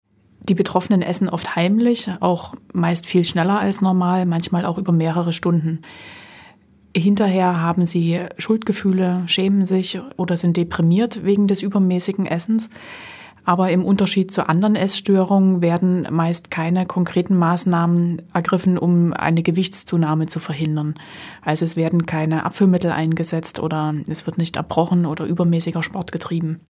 Radio O-Ton